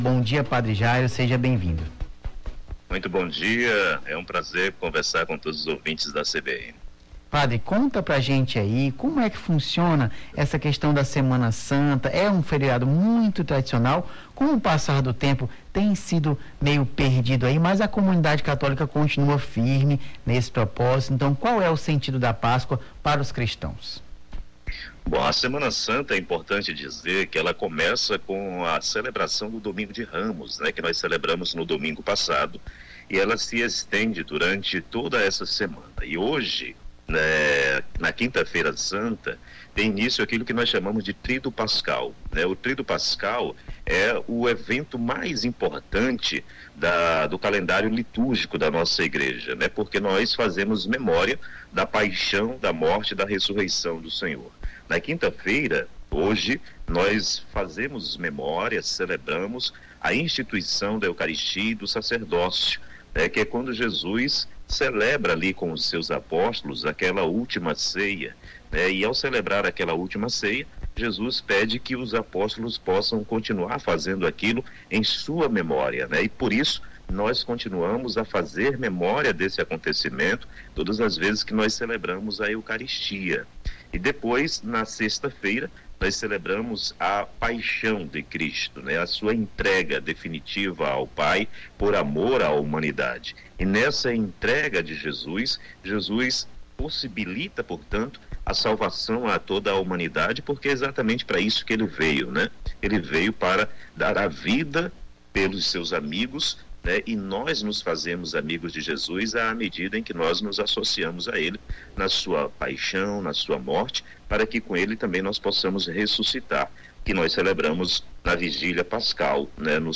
Além da reportagem